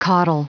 Prononciation du mot caudal en anglais (fichier audio)
Prononciation du mot : caudal